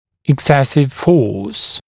[ɪk’sesɪv fɔːs] [ek-][ик’сэсив фо:с] [эк-]чрезмерная сила, избыточная сила